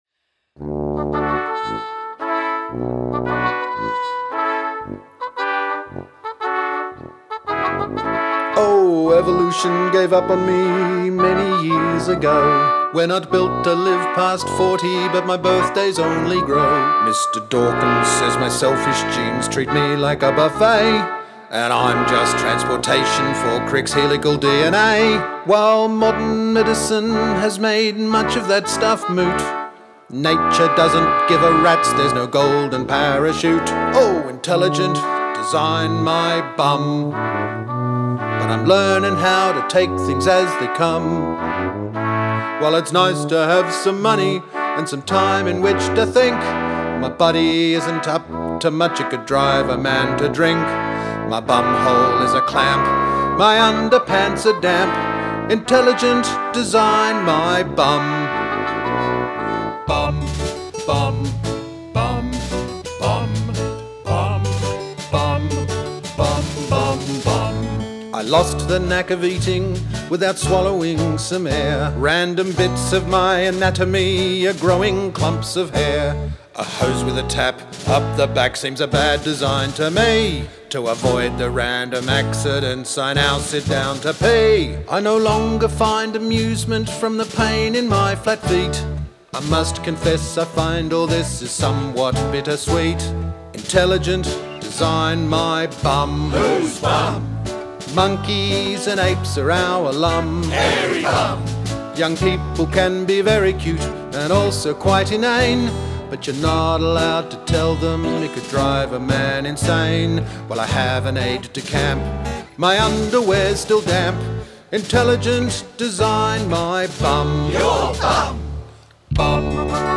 & Acoustic/Electric Guitars.
Keyboards, MIDI programming & Drums.
Trumpets
Vocals & Bass